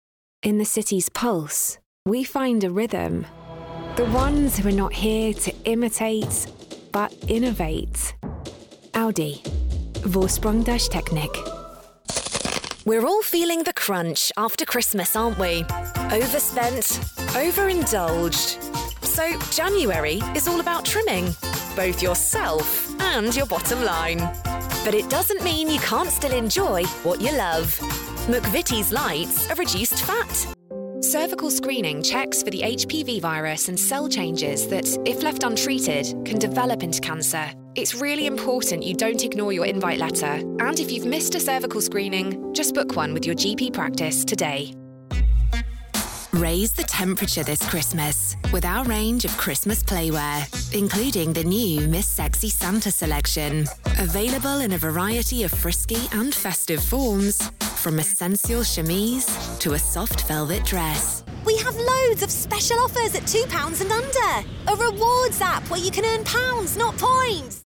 Anglais (britannique)
Entreprise
Chaleureux
Naturel